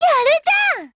贡献 ） 协议：Copyright，其他分类： 分类:语音 、 分类:少女前线:UMP9 您不可以覆盖此文件。